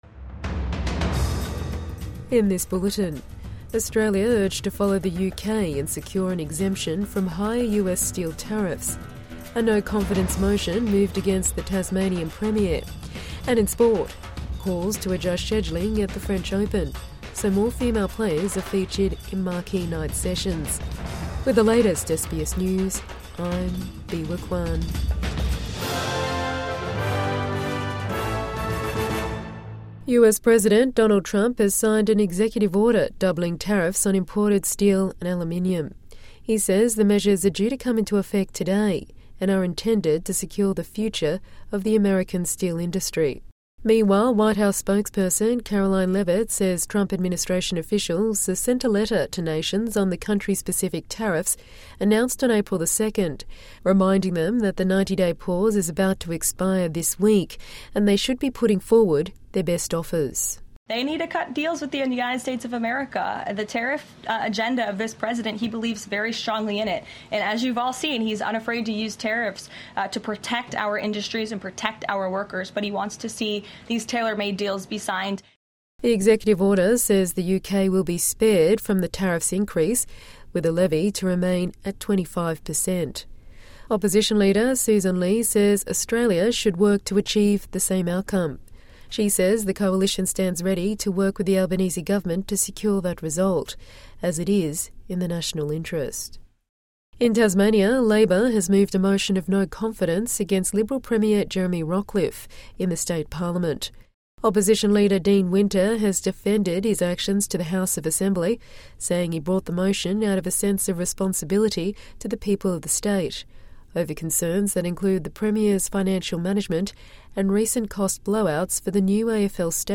Australia urged to secure an exemption from higher US tariffs | Midday News Bulletin 4 June 2025